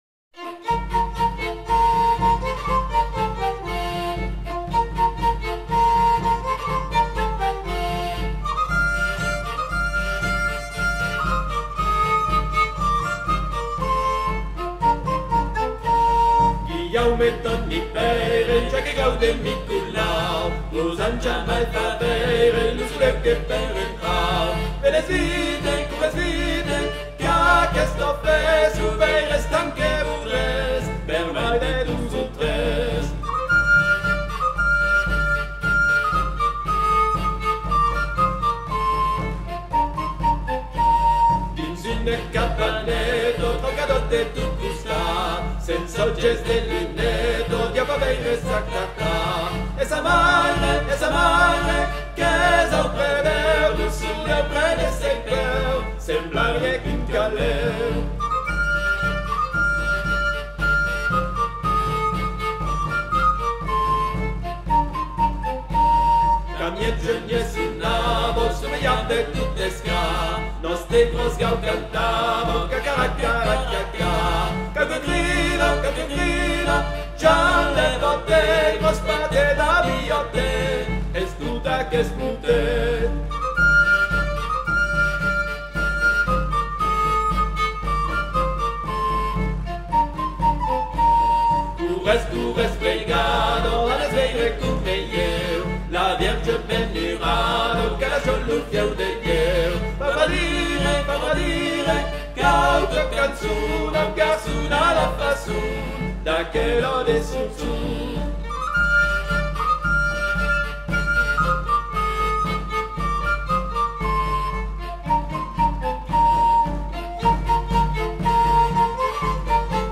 Provençal
guilhaume-toni-peire-ensemble-jehan-de-channey.mp3